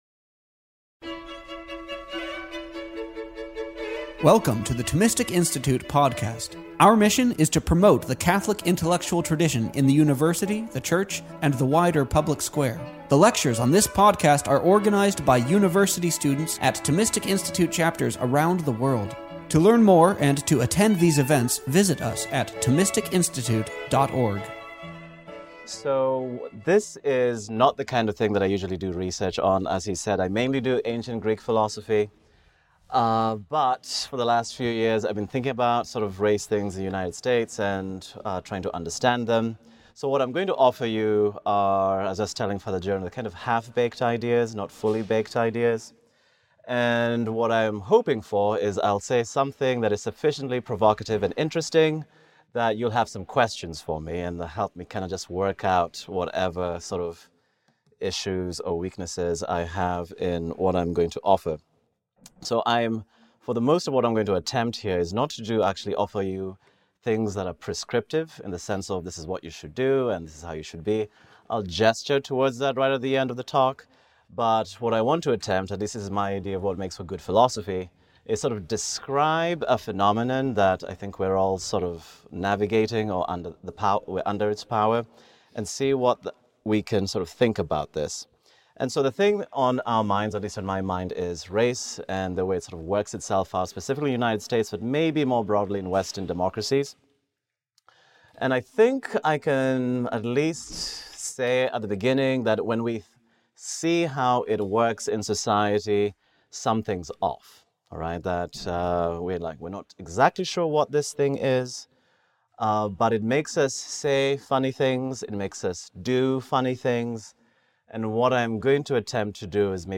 This talk was offered at our Civitas Dei Summer Fellowship program.